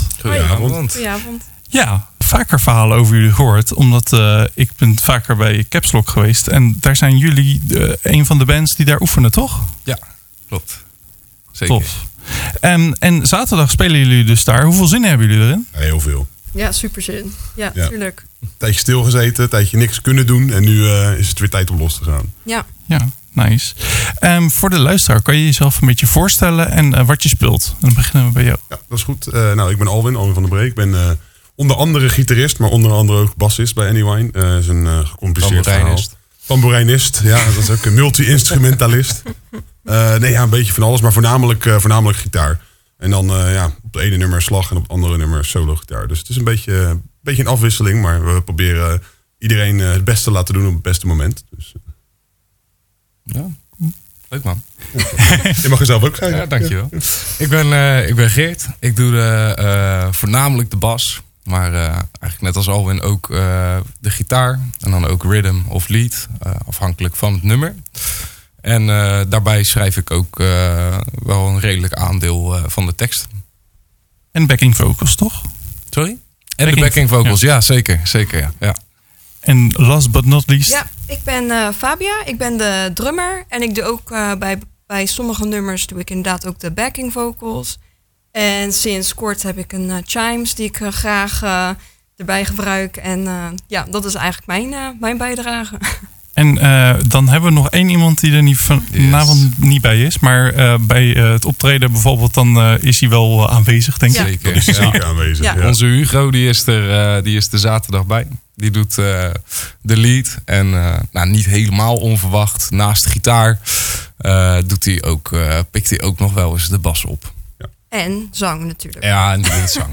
Hier hoor je het� hele interview met Annie Wine in Bang Your Radio .